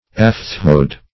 Search Result for " aphthoid" : The Collaborative International Dictionary of English v.0.48: Aphthoid \Aph"thoid\, a. [Aphtha + -oid.] Of the nature of aphth[ae]; resembling thrush.